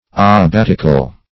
abbatical - definition of abbatical - synonyms, pronunciation, spelling from Free Dictionary Search Result for " abbatical" : The Collaborative International Dictionary of English v.0.48: Abbatical \Ab*bat"ic*al\ ([a^]b*b[a^]t"[i^]*kal), a. Abbatial.